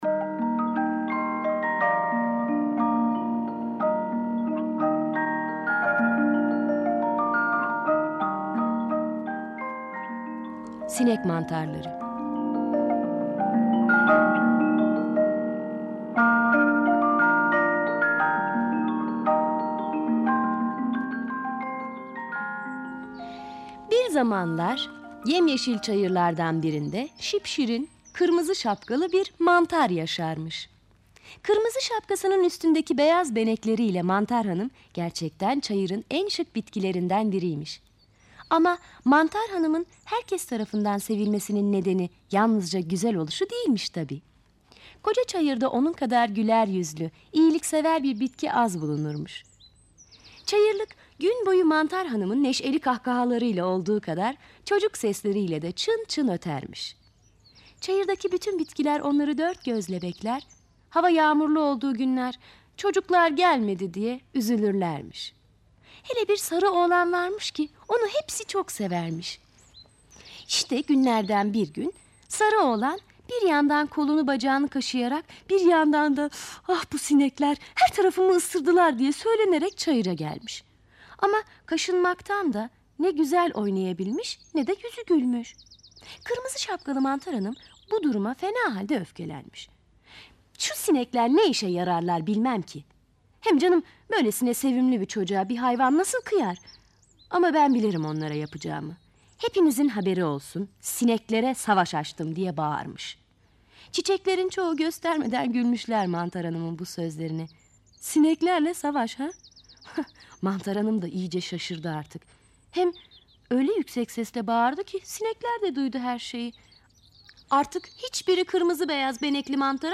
Sinek mantarları sesli masalı, mp3 dinle indir